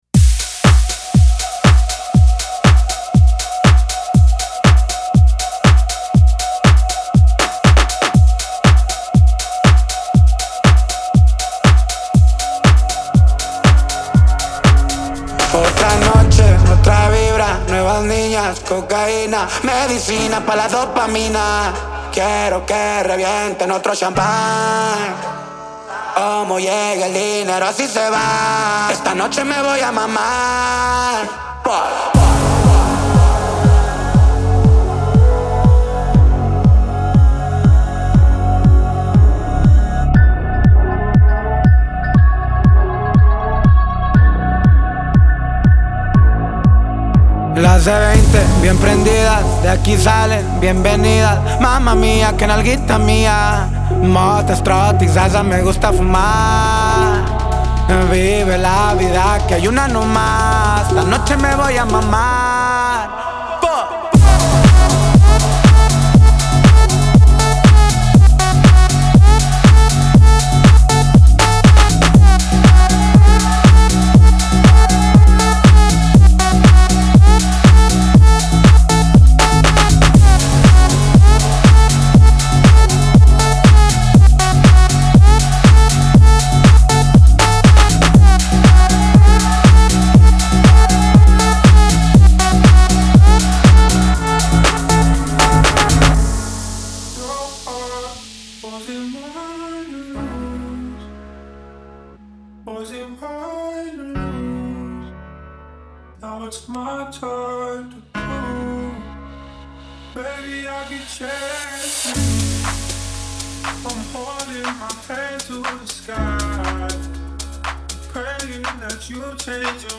• Жанр: House, Dance